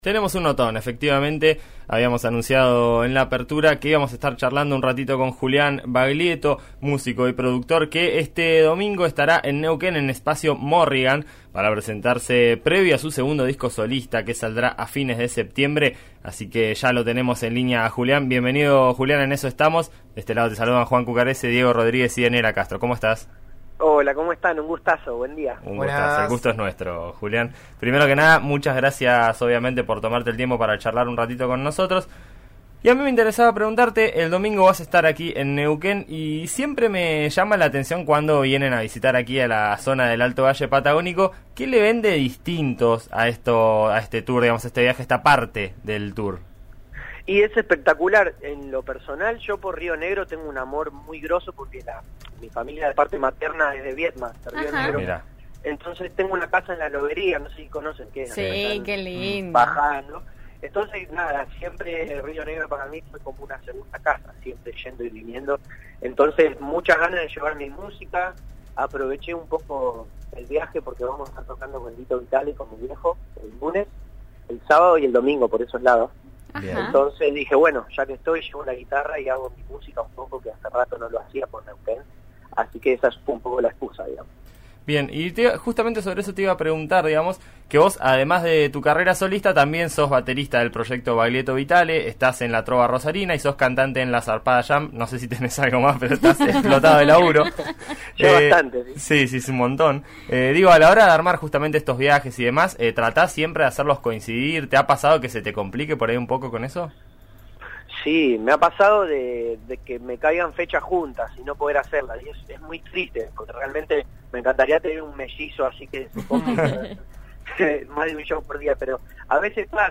Y en este contexto, charló con En Eso Estamos por RN RADIO, para repasar las sensaciones previas al espectáculo.